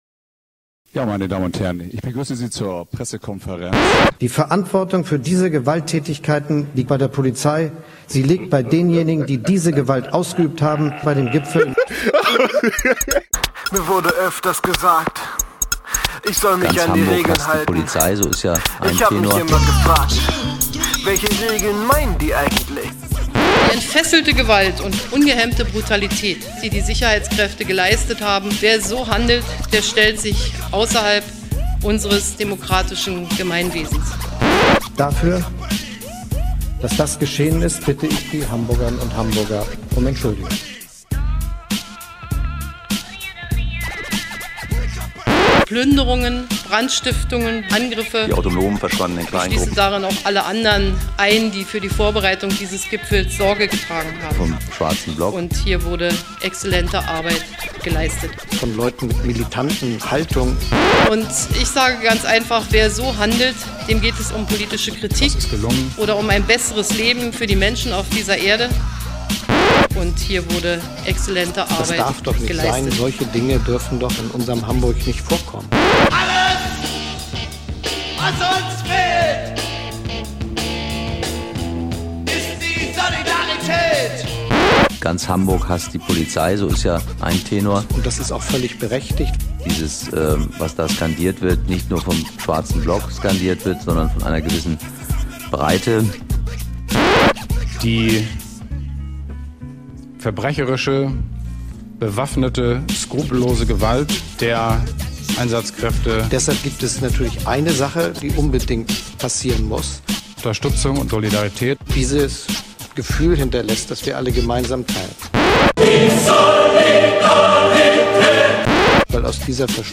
Hier hat uns ein Demo-Jingle erreicht – freie Radios, bedient euch!